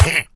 Minecraft Version Minecraft Version 25w18a Latest Release | Latest Snapshot 25w18a / assets / minecraft / sounds / mob / illusion_illager / hurt2.ogg Compare With Compare With Latest Release | Latest Snapshot
hurt2.ogg